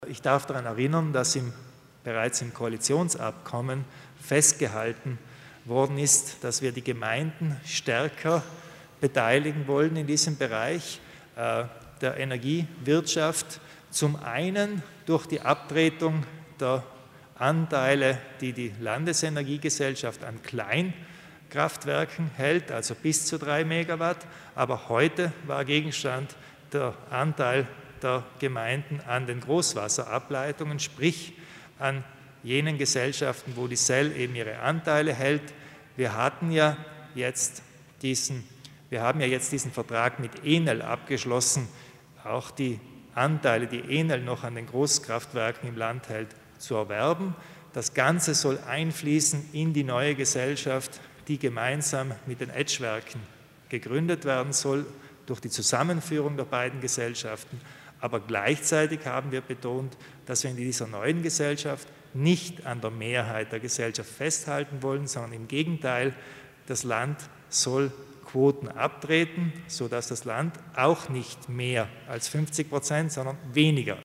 Landeshauptmann Kompatscher erläutert die Neuigkeiten zum Thema Energie